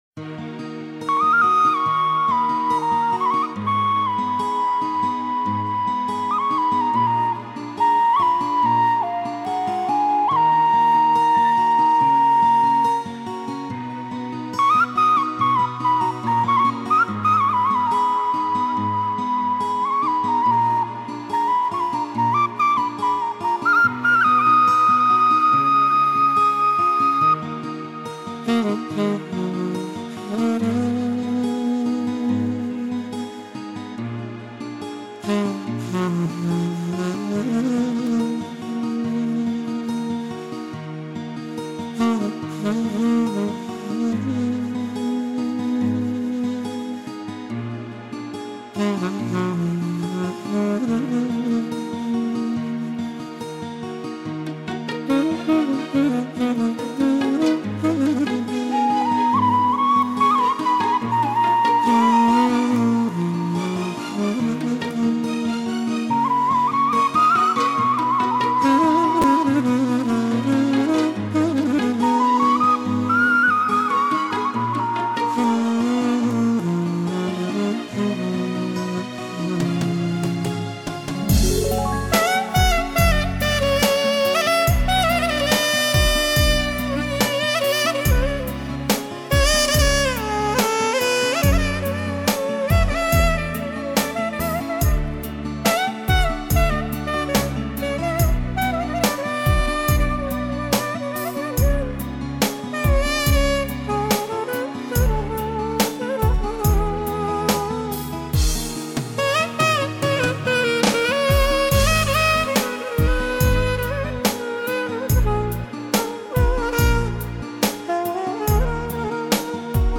КРАСИВАЯ МЕЛОДИЯ ДЛЯ ДУШИ... (ФЛЕЙТА, ДУДУК, САКСОФОН)